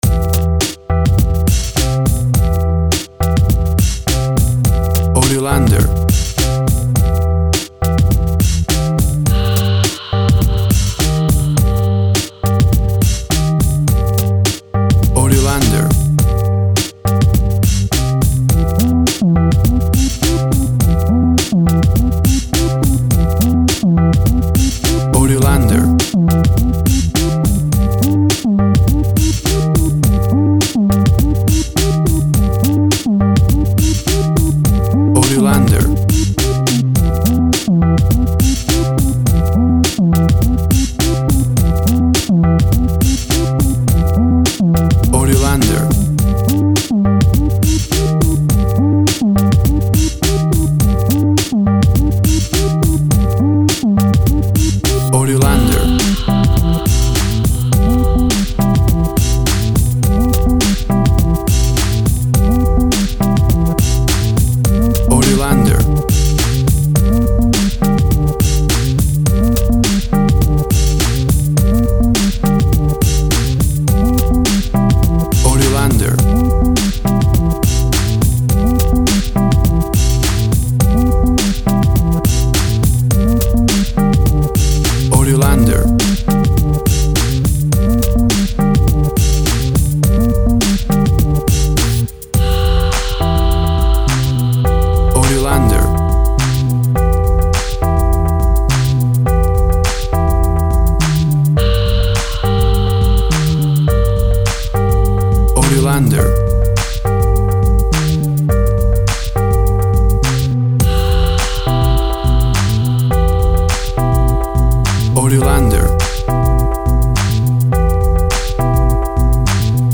Tempo (BPM) 100